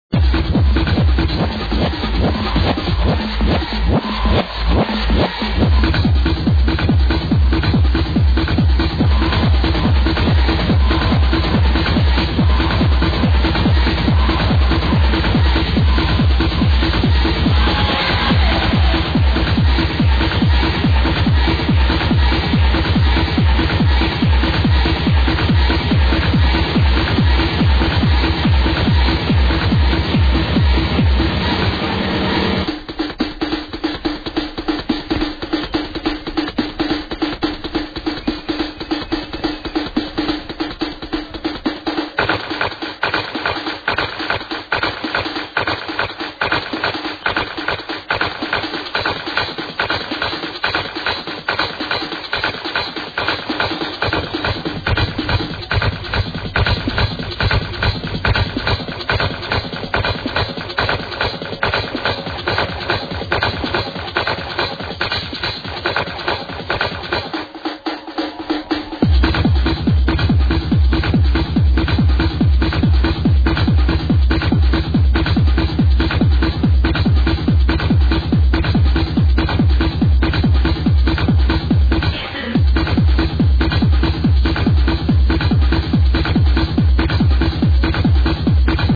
Live @ Atlantis 06.27.00